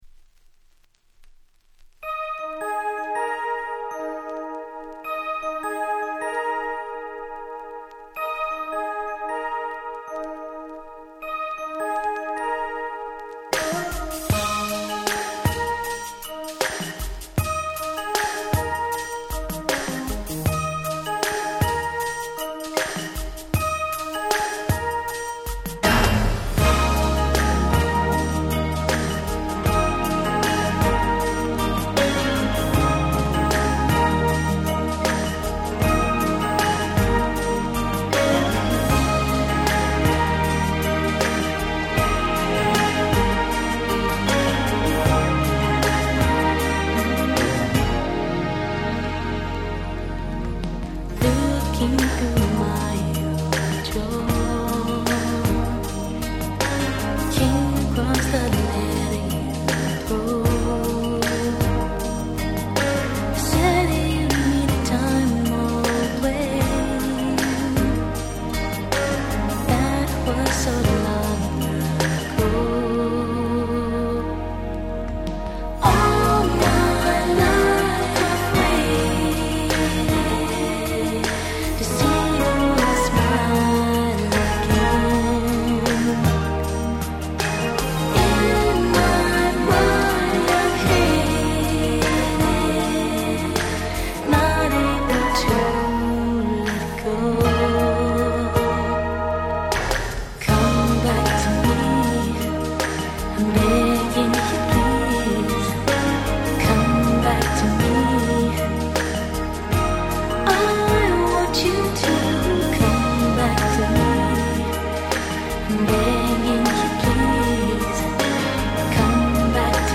90' Super Hit Slow Jam !!
彼女の曲の中でも屈指の名バラード！！
本当に本当に素敵なバラードです。